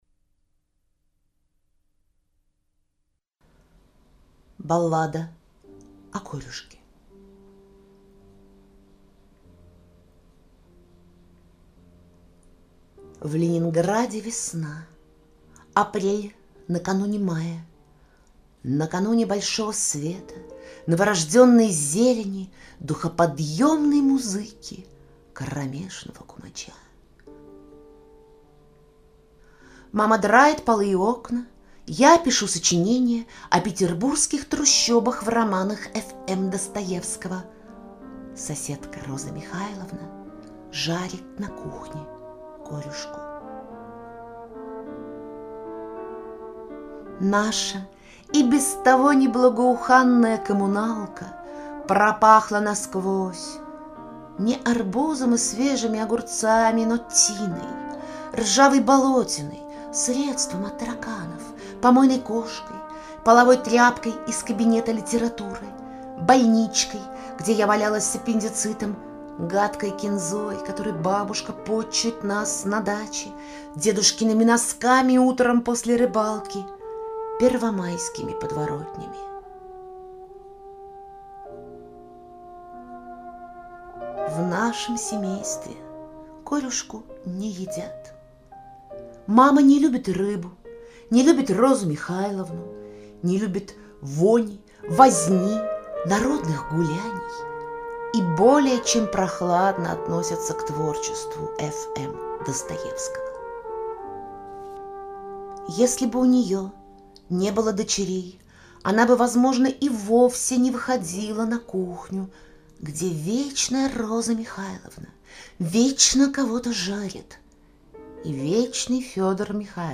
Музыкально-поэтические эксперименты